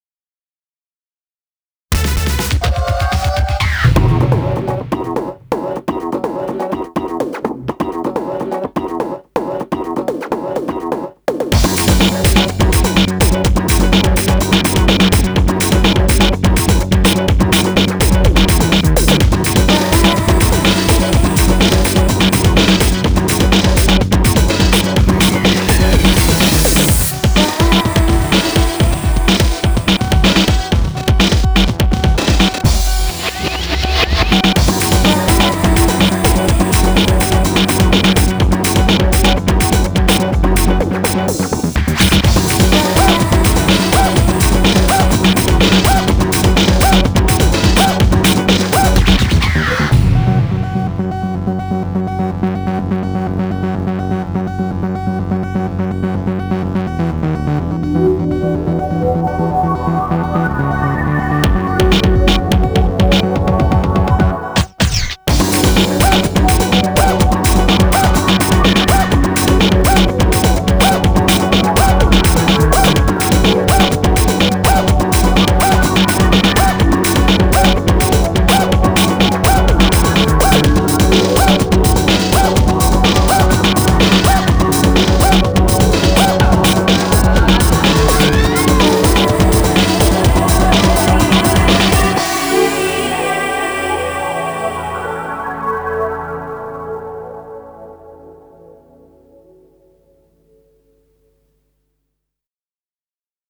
BPM125
Audio QualityLine Out